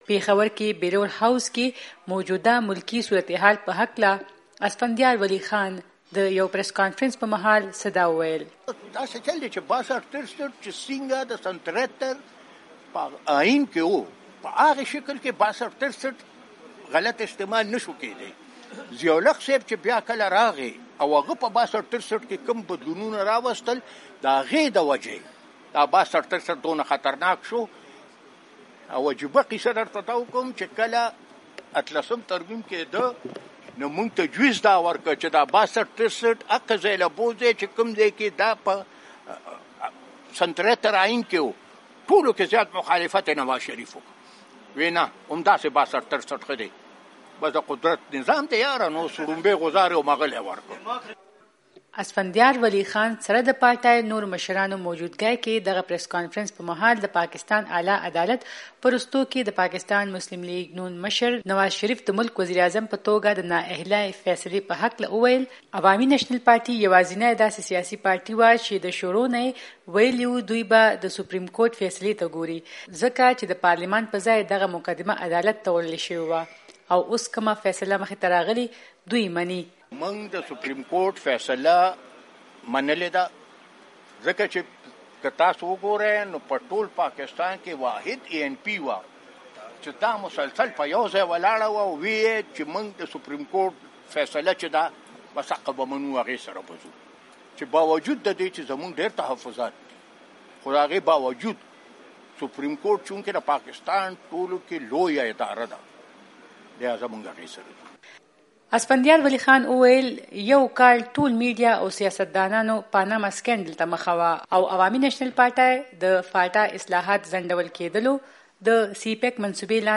اسفندیار ولي خان خبري کانفرنس